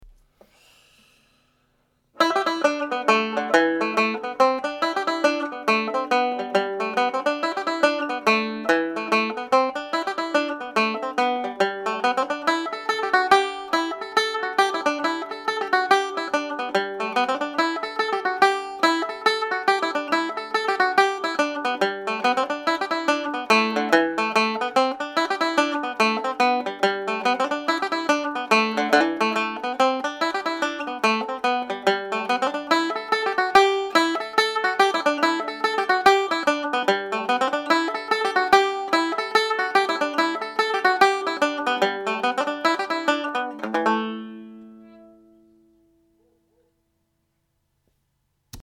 It is a ‘Hop Jig’ although some say it is a slip jig.
Rocky Road to Dublin played at normal speed